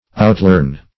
Outlearn \Out*learn"\, v. t.